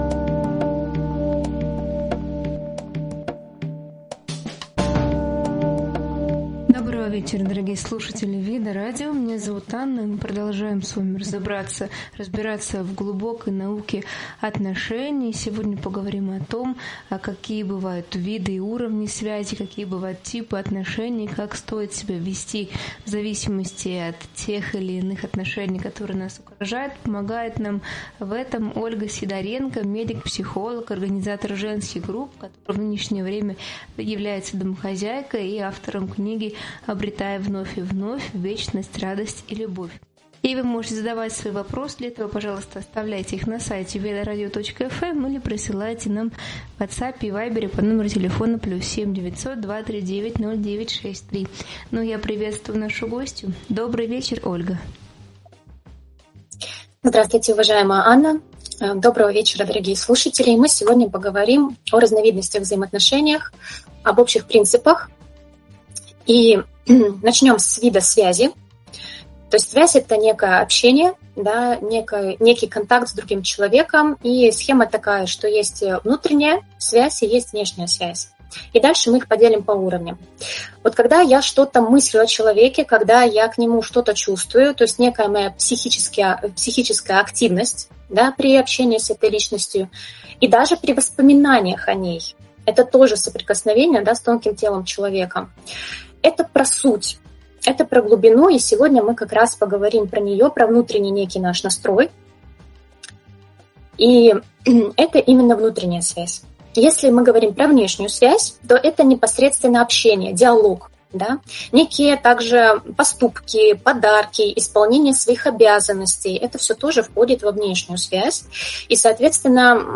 В эфире обсуждаются ключевые аспекты гармоничных отношений: алгоритмы взаимодействия, безопасное общение и обратная связь в семье, роли мужа и жены, принципы компромисса, влияние финансовых вопросов и привычек на динамику отношений. Говорится о важности саморефлексии, внутренней работы, распределения обязанностей и поиска поддержки для построения крепких и ценных связей в семье.